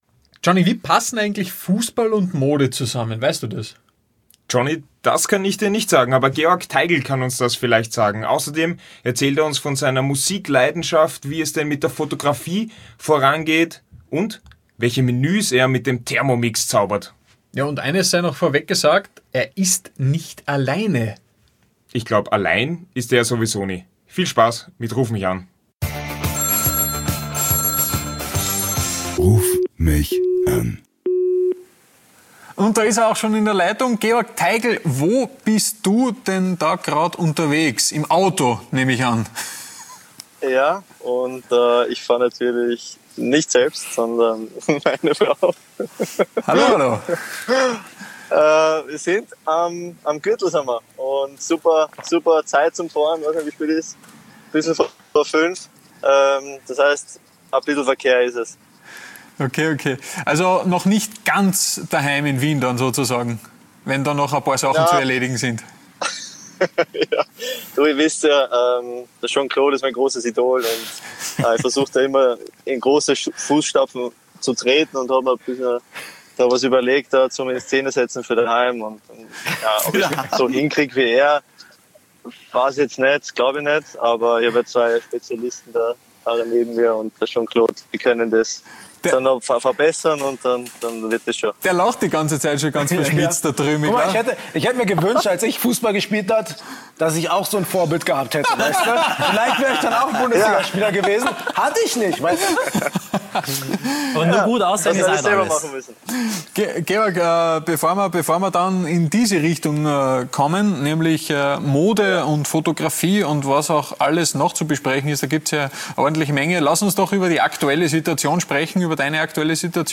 RUF MICH AN ist der Podcast des Sky Sport Austria Kultformats Die ABSTAUBER. Jede Woche rufen sie im Rahmen der TV-Show einen prominenten Fußballer oder Sportler via Facetime an und plaudern mit ihm über alles.